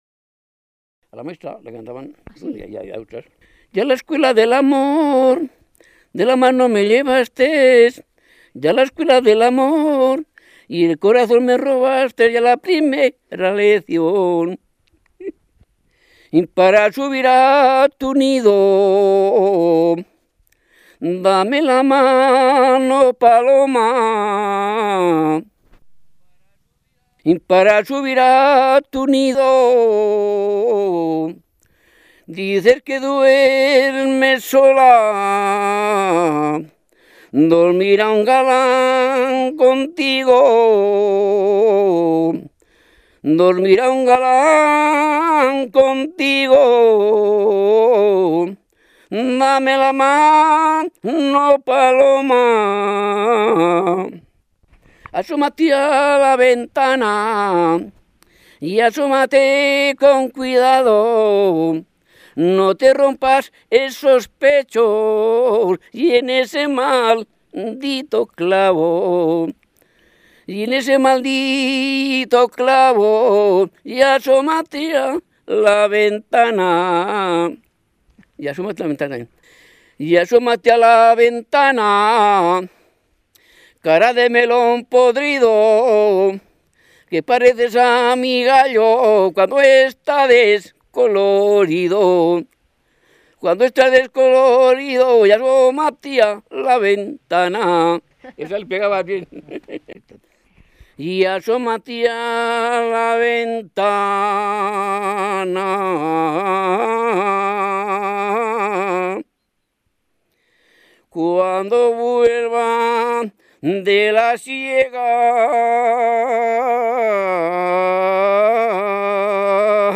Clasificación: Cancionero
Lugar y fecha de recogida: Gallinero de Cameros, 21 de septiembre de 2005
Descubrimos en su canto tres tonadas distintas de jota.